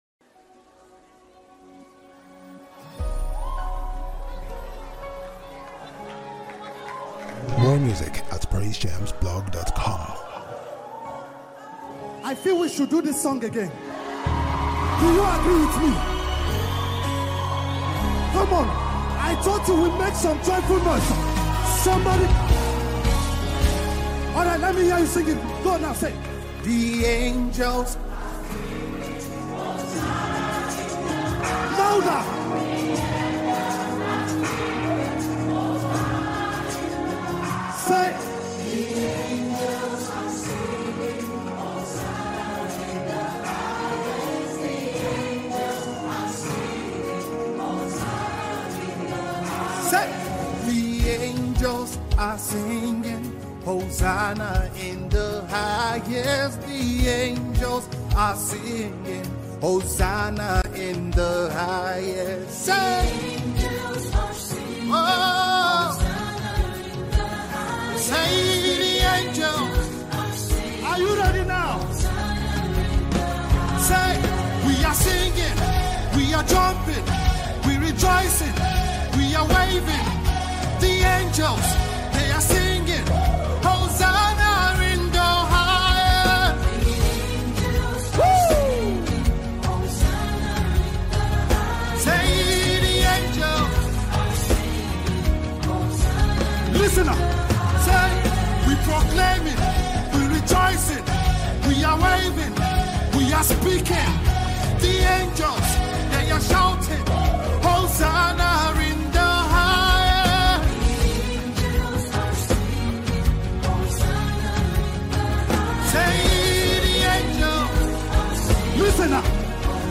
Contemporary gospel